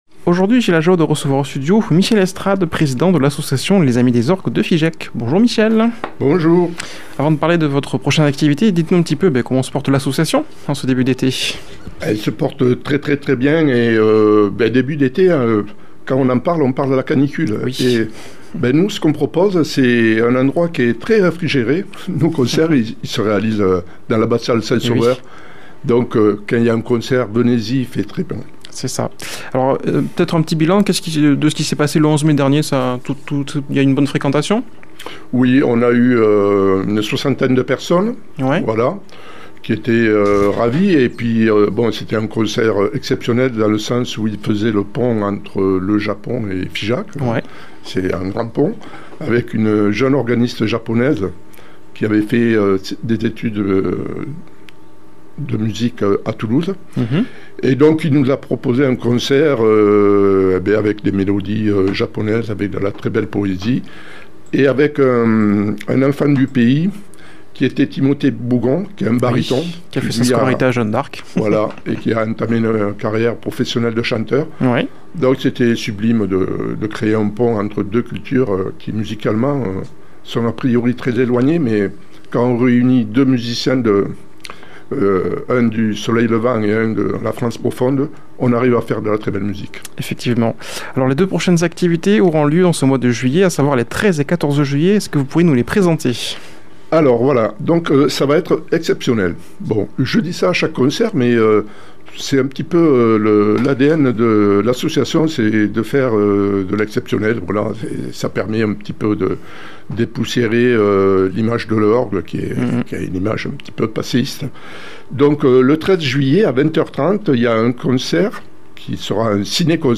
invité au studio